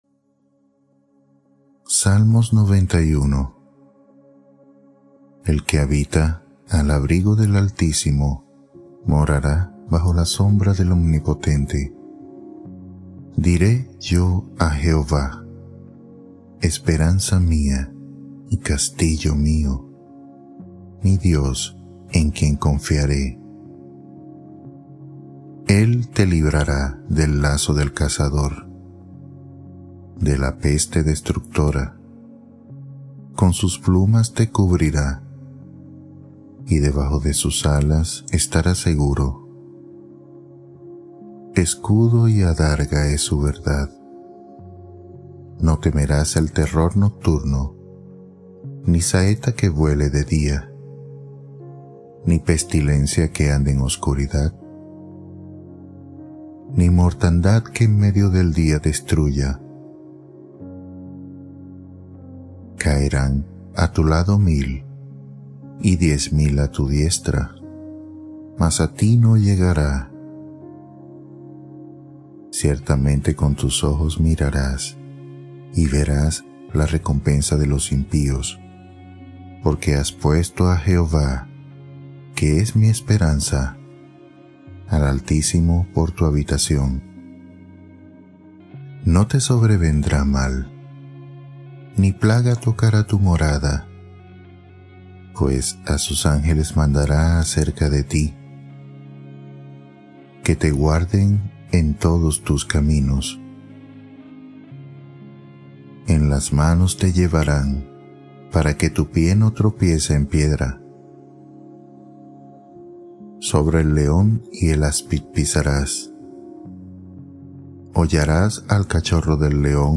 Proverbios, salmos y promesas de Dios | Biblia en audio para descansar